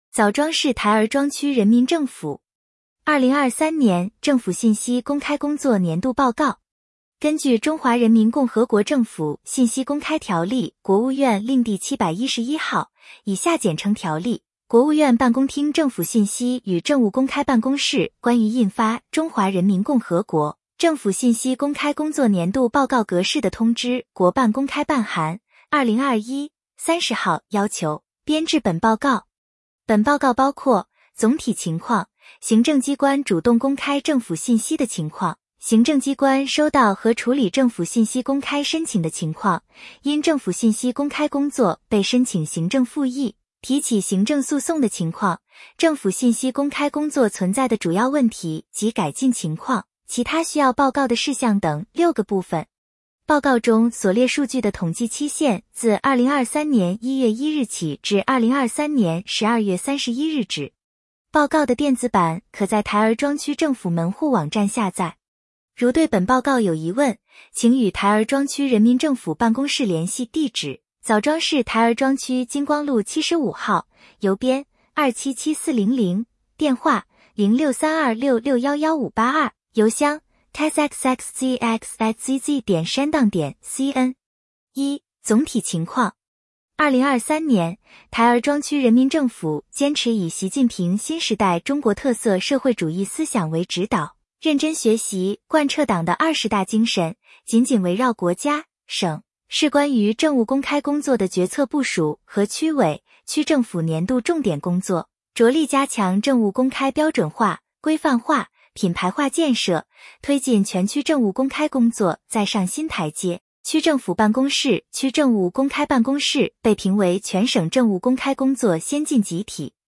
点击接收年报语音朗读 枣庄市台儿庄区人民政府2023年政府信息公开工作年度报告 作者：台儿庄区人民政府 来自：台儿庄区人民政府 时间：2024-02-08 根据《中华人民共和国政府信息公开条例》（国务院令第 711 号，以下简称《条例》）、《国务院办公厅政府信息与政务公开办公室关于印发＜中华人民共和国政府信息公开工作年度报告格式＞的通知》（国办公开办函〔 2021 〕 30 号）要求，编制本报告。